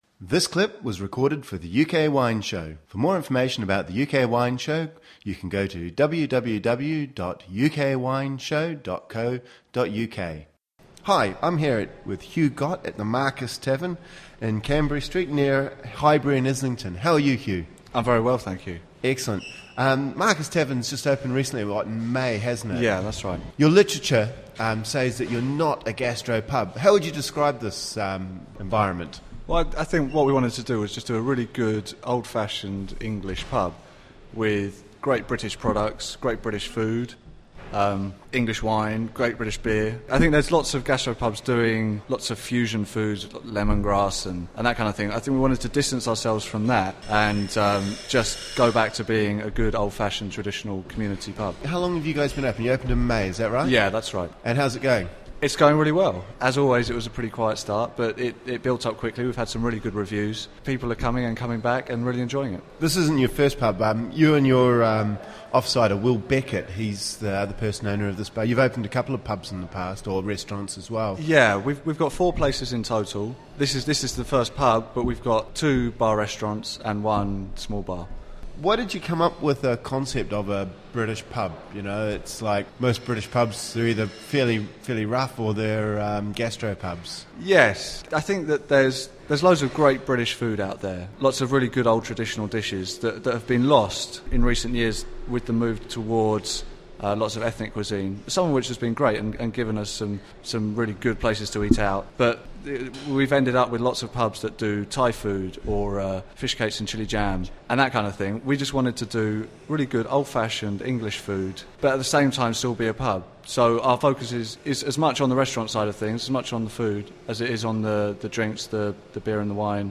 UK Wine Show 10 Marquess Tavern 4Aug07 Interview Only.mp3